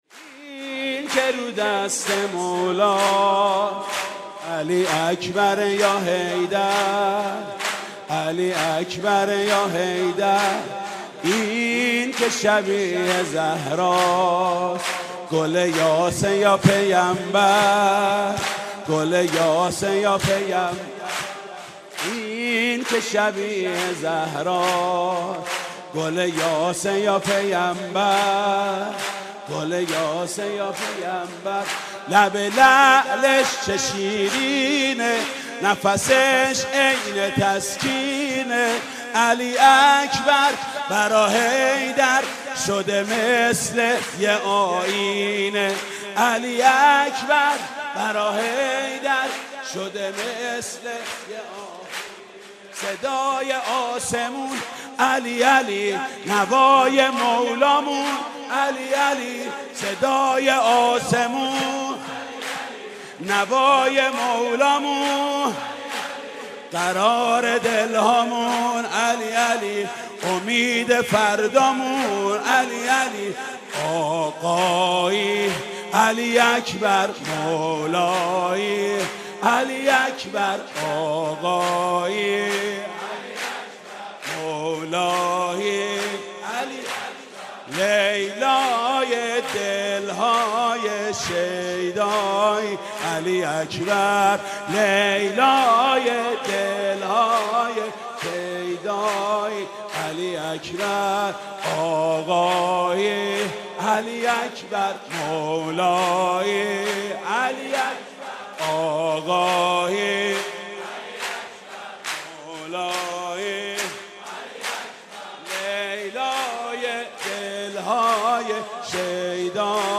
سرود: این که رو دست مولاست